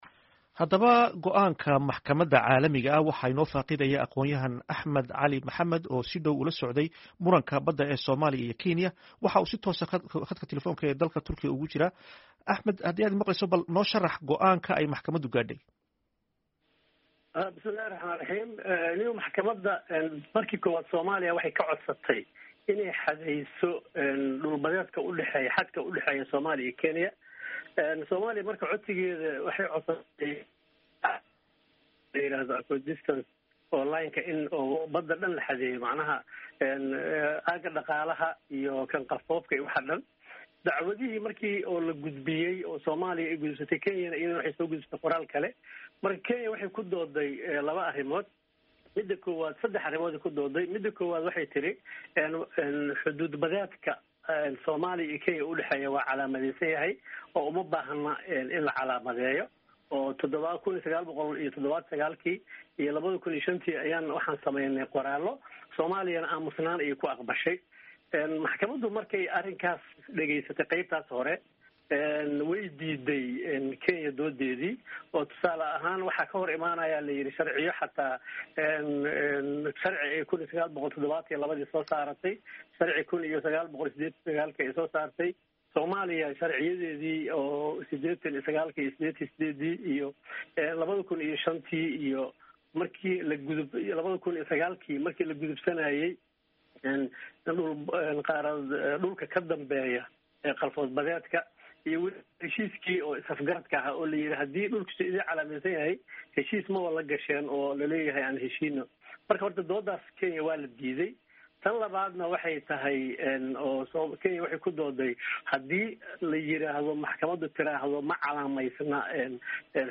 Wareysi
Xukunka ICJ